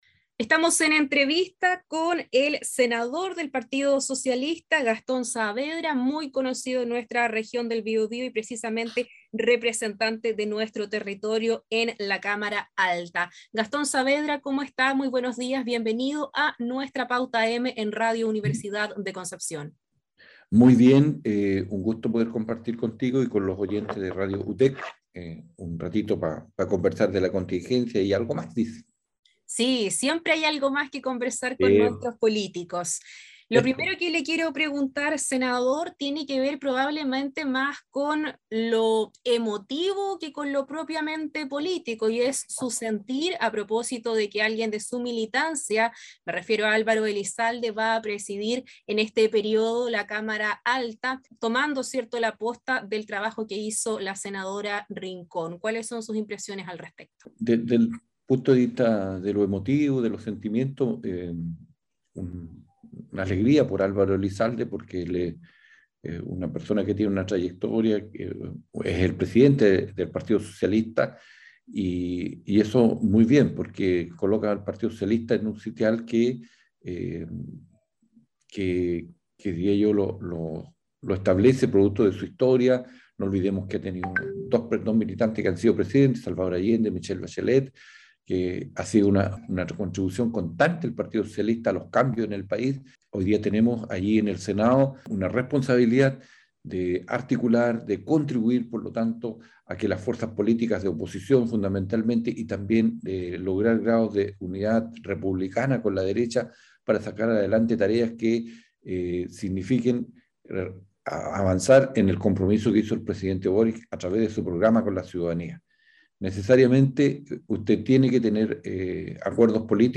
El senador por el Biobío, Gastón Saavedra, conversó con Radio UdeC sobre la intensa agenda que marcará el trabajo en el Congreso durante este año y los desafíos que impone la instalación del nuevo gobierno.
ENTREVISTA-GASTON-OK.mp3